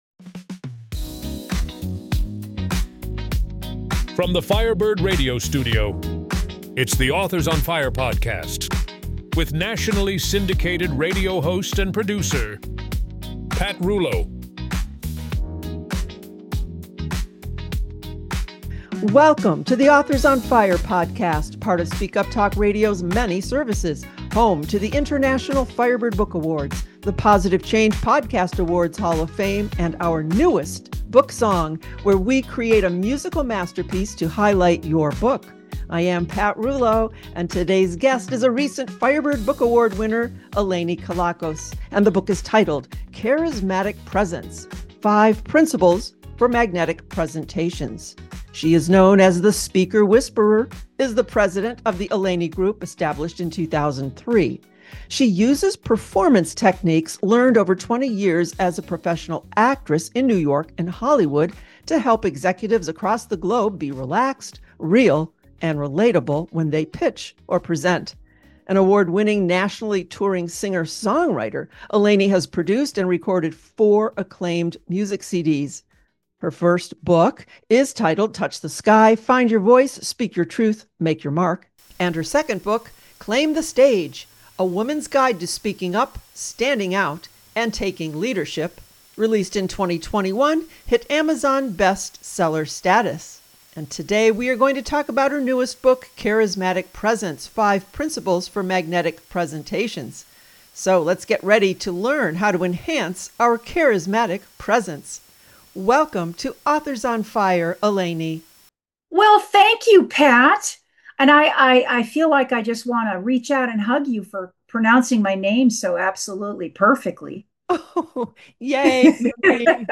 Author Interview – CHARISMATIC PRESENCE